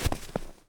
snow_place.ogg